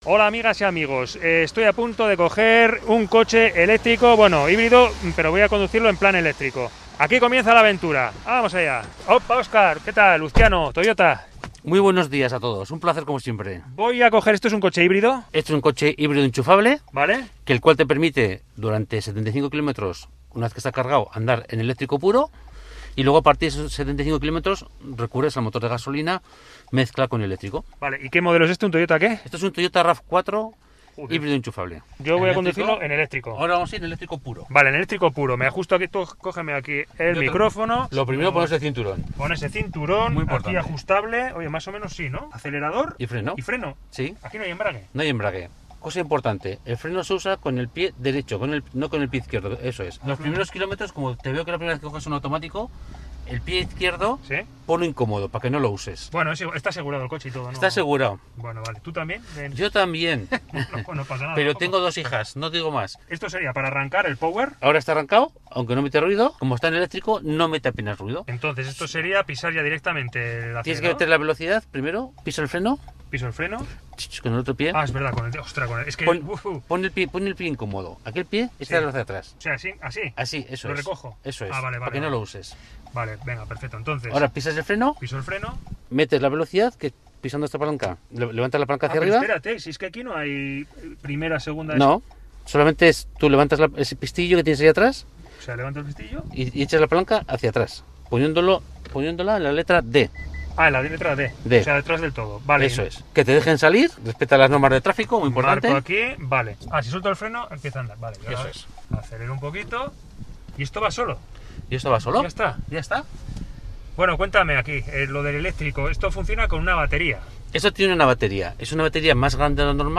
Audio: Reportaje: Conduciendo un eléctrico